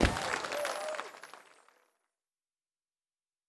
Special & Powerup (35).wav